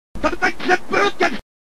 streetfighter-hurricane-kick-tatsumaki-senpuu-kyaku.mp3